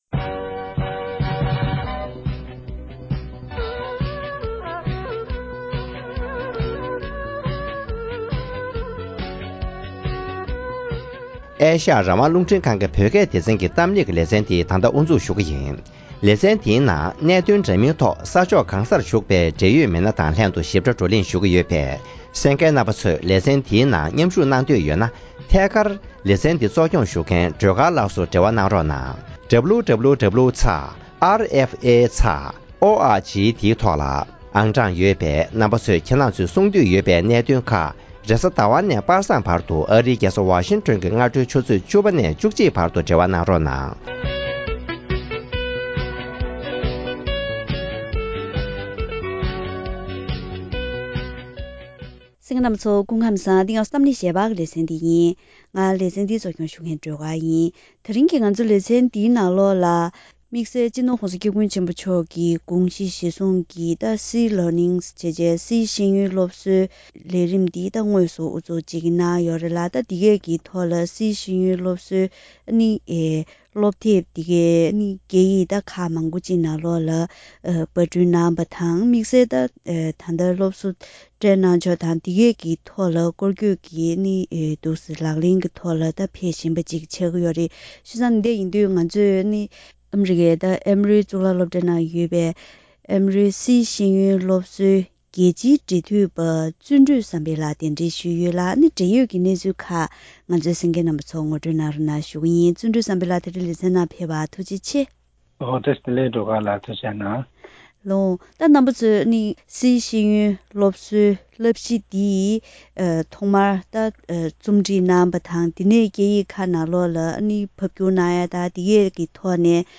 སྤྱི་ནོར་༧གོང་ས་སྐྱབས་མགོན་ཆེན་པོ་མཆོག་གི་དགོངས་གཞི་བཞིན་སཱི་ཤེས་ཡོན་སློབ་གསོའི་བསླབ་གཞི་གཏན་འབེབས་དང་། སྐད་ཡིག་འདྲ་མིན་ནང་ཕབ་བསྒྱུར་གནང་སྟེ་ཡུལ་གྲུ་མང་པོའི་ནང་རྒྱ་ཁྱབ་ངང་སློབ་གསོ་སྤེལ་འགོ་ཚུགས་ཡོད་པས་སཱི་ཤེས་ཡོན་སློབ་གསོ་ཞེས་པའི་ཀུན་སྤྱོད་ཀྱི་སློབ་གསོའི་ཐོག་སློབ་གྲྭ་ཁག་ནས་དོ་སྣང་དང་རྒྱ་ཁྱབ་ངང་སློབ་གསོ་གནང་ཕྱོགས་སོགས་ཀྱི་འབྲེལ་ཡོད་སྐོར་ལ་བཀའ་མོལ་ཞུས་པ་ཞིག་གསན་རོགས་གནང་།